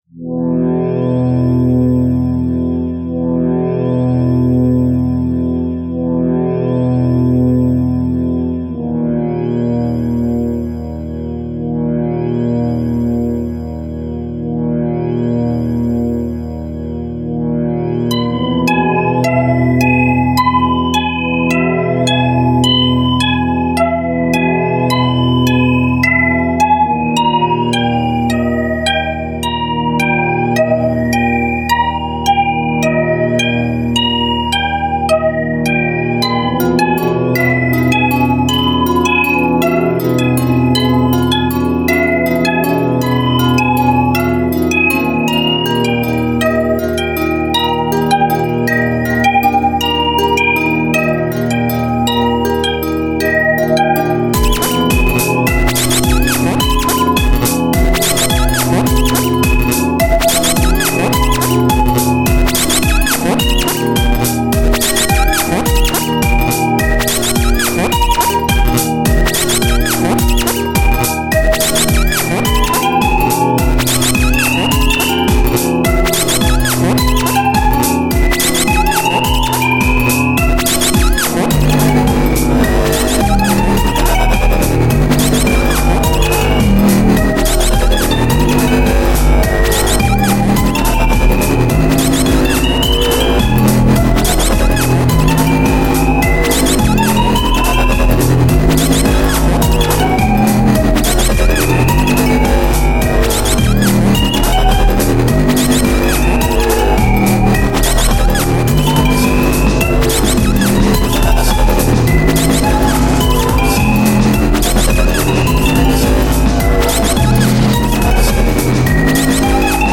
interesting sounds, probably just attracted to the wubs. also uses relatively peaceful instruments, which add an appropriate contrast. the heavy wubs would create tension as well.